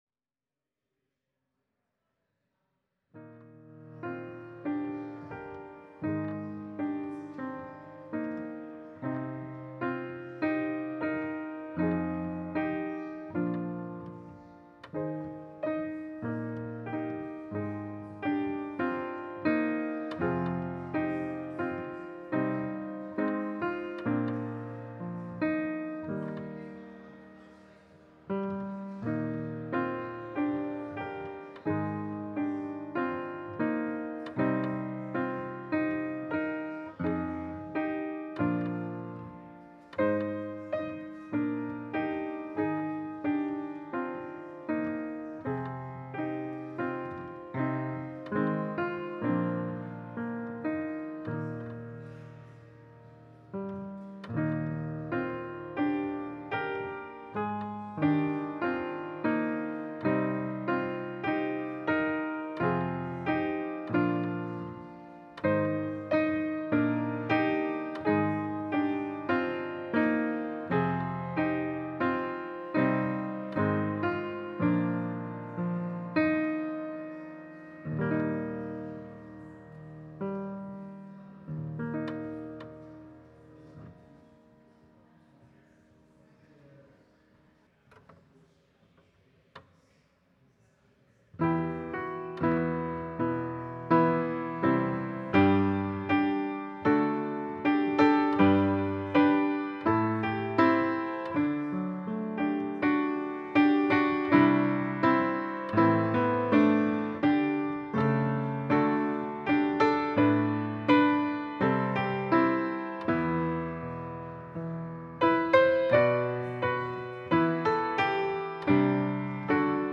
Passage: Luke 14: 25-33 Service Type: Sunday Service